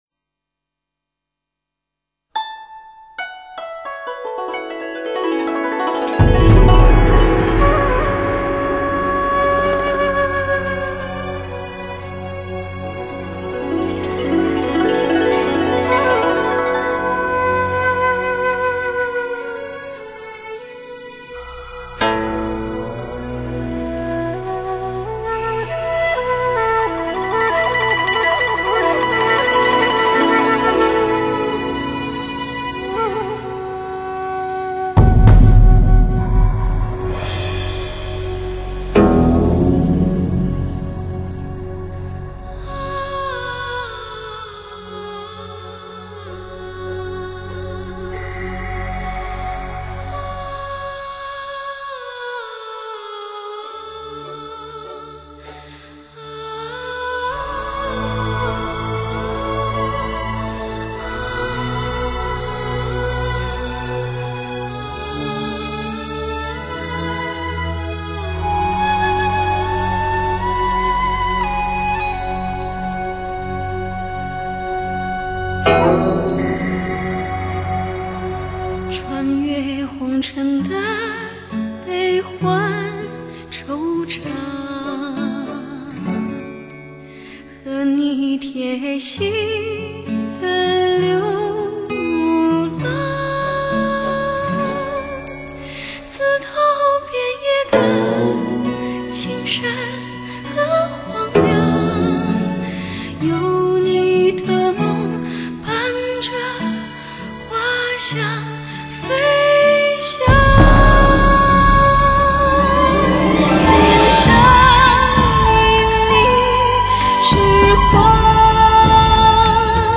弥足珍贵的嗓音 穿梭在红尘的妙音鸟
演唱近百部影视歌曲的传奇